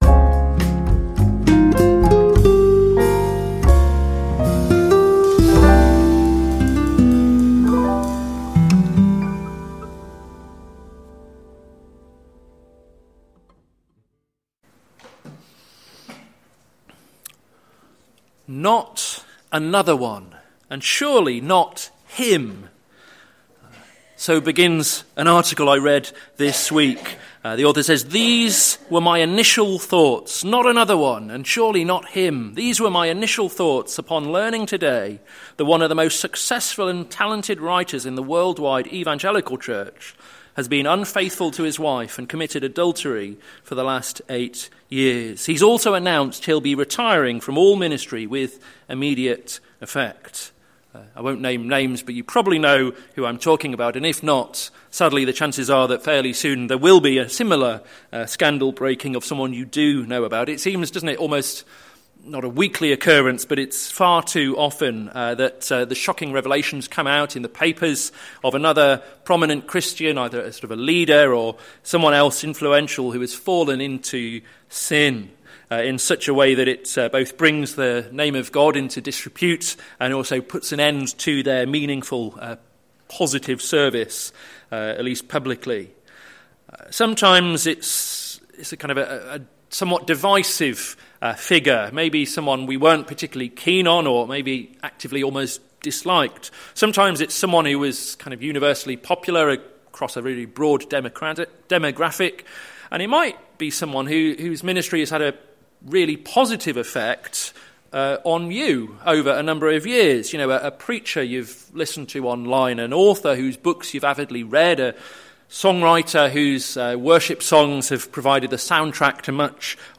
Sermon Series - May the Lord establish His Word - plfc (Pound Lane Free Church, Isleham, Cambridgeshire)